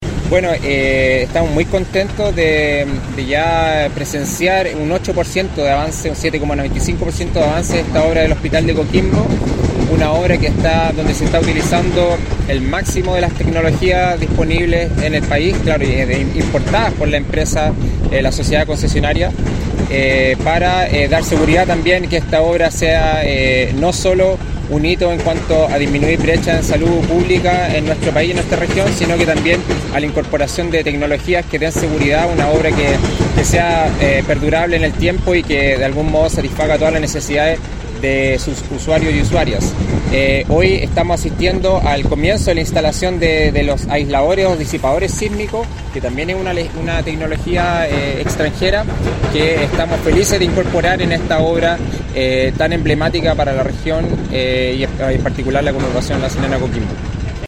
Por su parte, el SEREMI de Obras Públicas, Javier Sandoval, valoró el uso de la tecnología en el proyecto.
AISLANTE-SISMICO-Javoer-Sandoval-Seremi-MOP.mp3